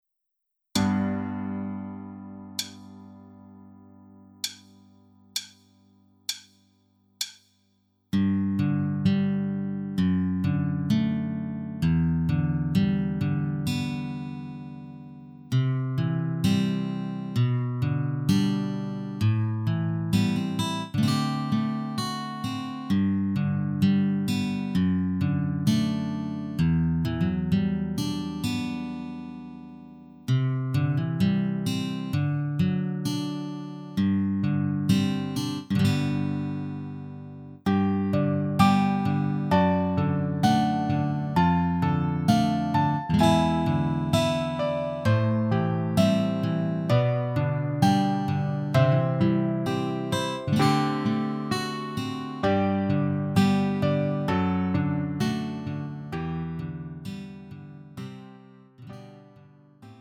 음정 -1키 3:59
장르 구분 Lite MR